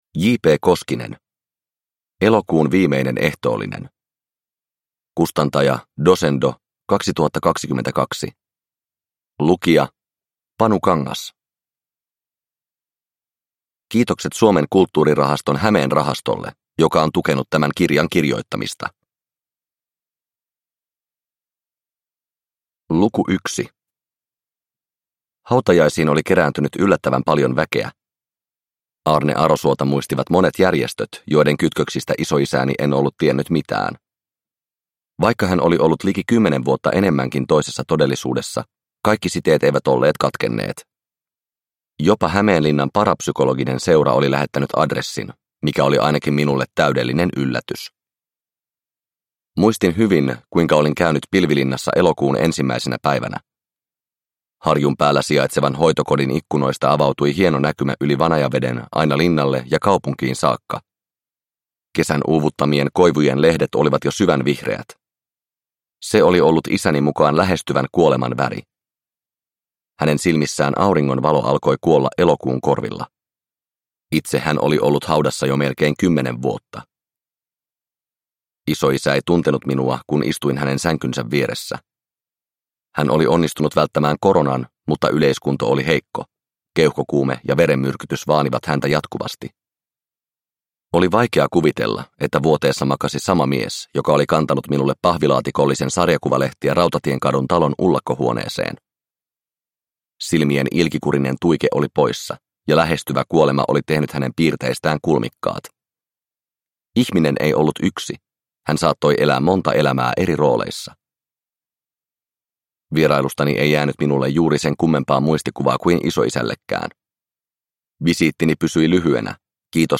Elokuun viimeinen ehtoollinen – Ljudbok – Laddas ner